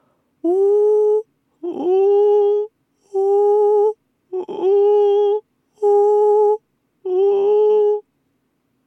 呼気で発声した後、落ち着いて一息ついた後に吸気にしようとすると意味がありません。
音量注意！